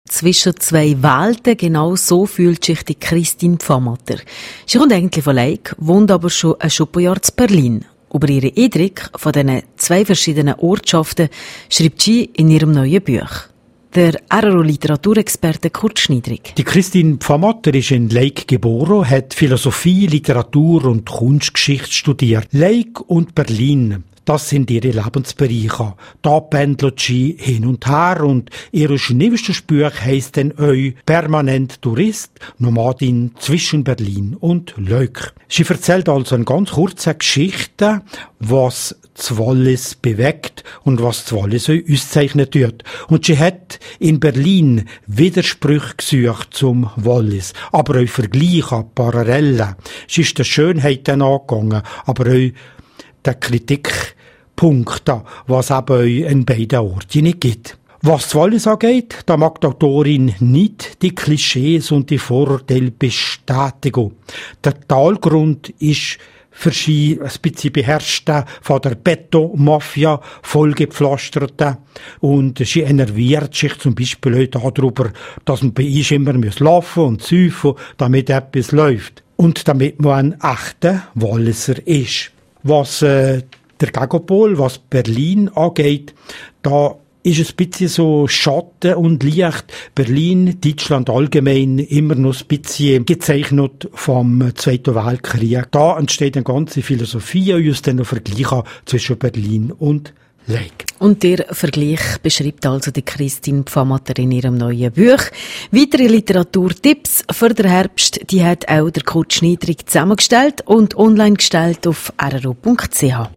Moderation: